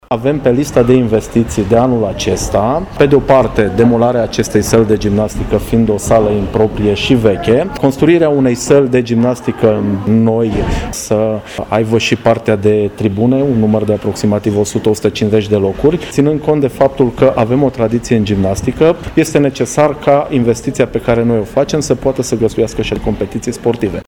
Municipalitatea a anunțat că doreşte construirea unei săli moderne, complet utilată, care să servească cerinţelor sportului de performanţă,a anunțat viceprimarul Mihai Costel: